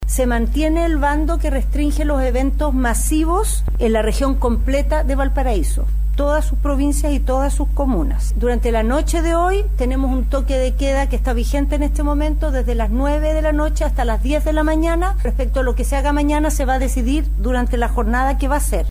Esas fueron las palabras de la ministra del Interior, Carolina Tohá, por el cierre de la actividad comercial de diferentes negocios en la región de Valparaíso en el marco del megaincendio de febrero de 2024.